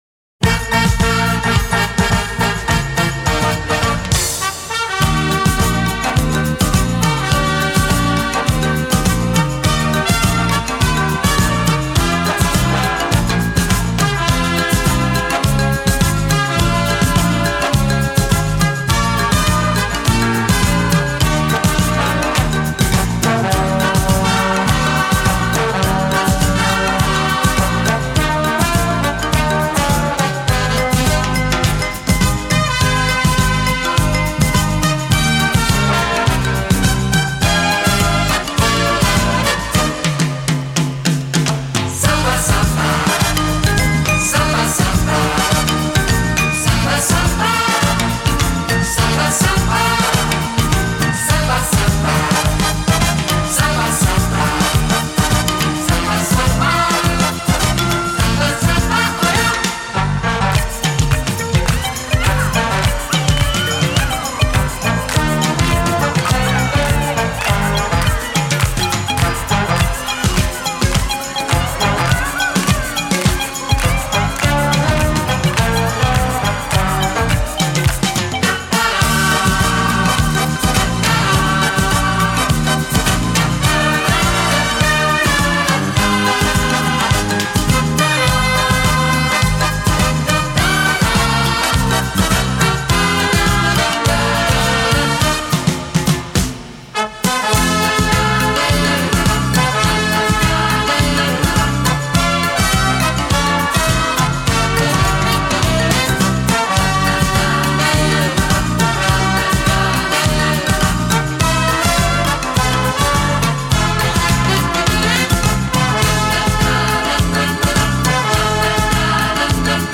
Genre:Easy Listening
(Samba 52)